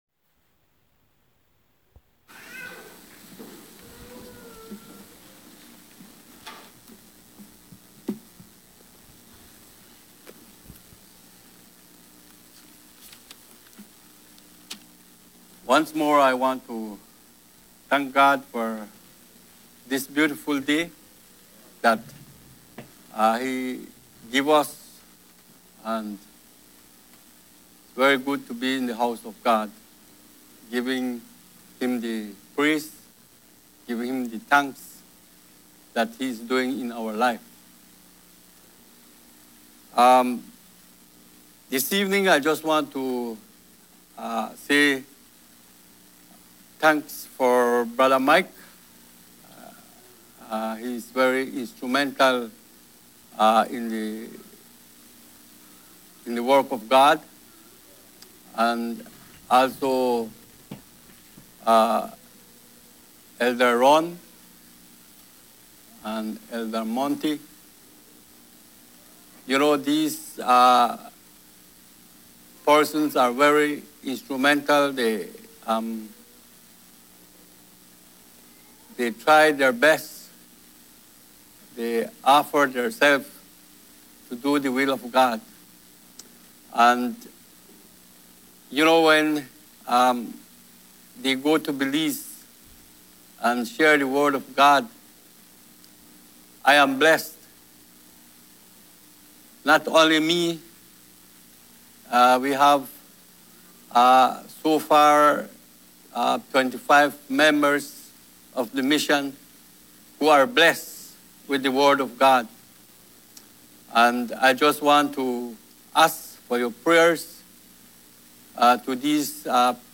Passage: 2 Peter 3:10 Service Type: Sunday Evening